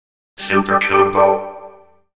super_combo.ogg